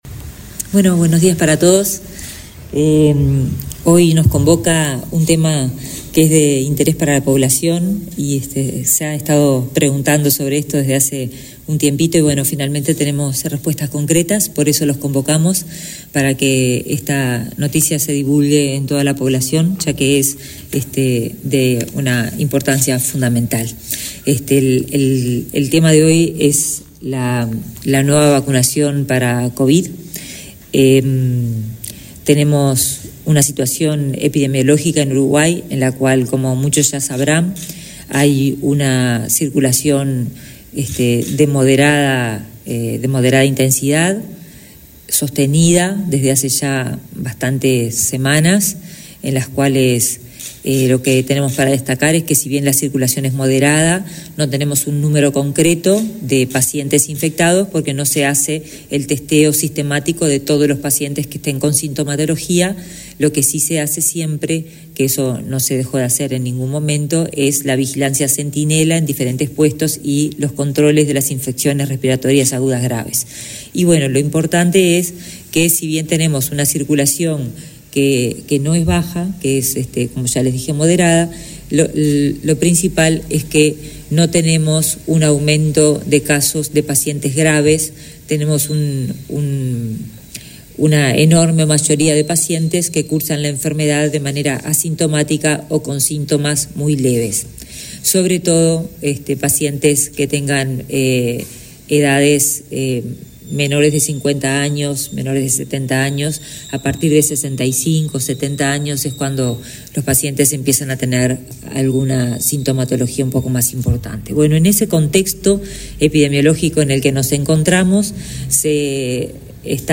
Palabras de autoridades del MSP 16/01/2024 Compartir Facebook X Copiar enlace WhatsApp LinkedIn La ministra de Salud Pública, Karina Rando, y el subsecretario de la cartera, José Luis Satdjian, informaron a la prensa acerca de un nuevo período de vacunación contra la covid-19.